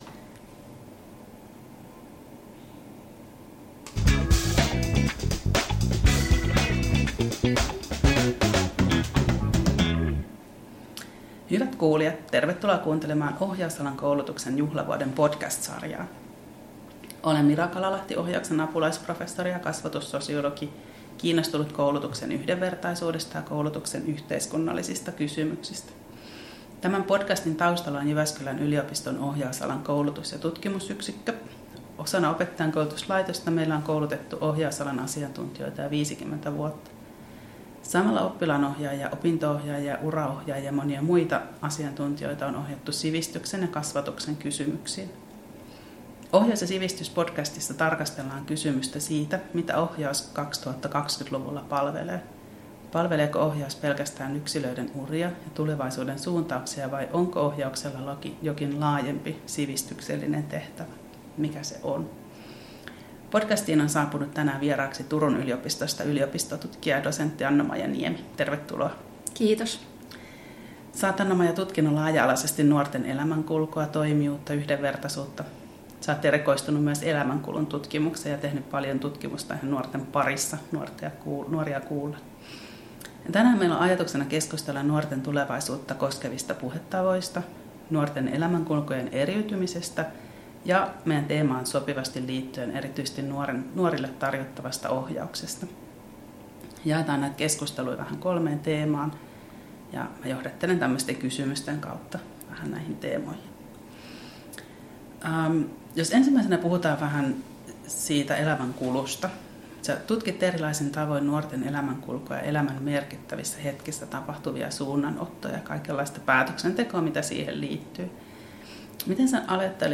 Keskustelijoina